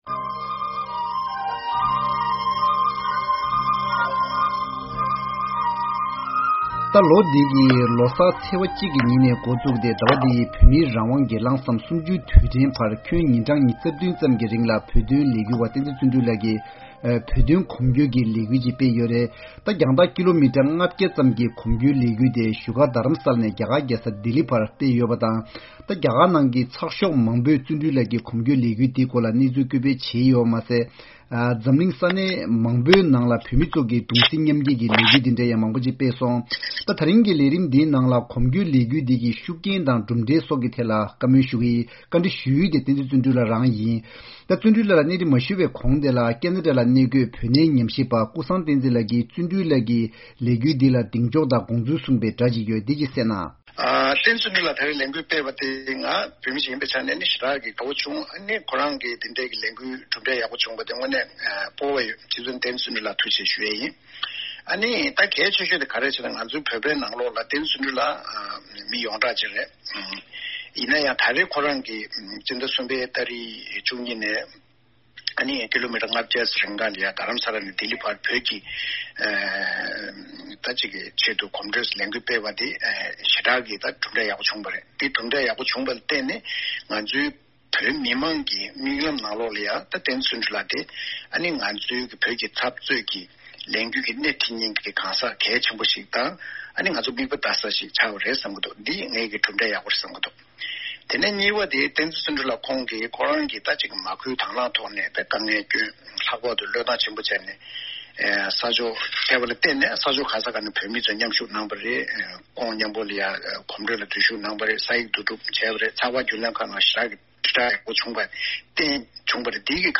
༄༅། བདུན་ཕྲག་འདིའི་བགྲོ་གླེང་མདུན་ཅོག་ལས་རིམ་ནང་བོད་མི་ཁ་རྐྱང་གིས་ལས་འགུལ་སྤེལ་བ་དེའི་ཤུགས་རྐྱེན་དང་ནུས་པ་བཅས་ཀྱི་སྐོར་གླེང་མོལ་ཞུ་རྒྱུ་ཡིན།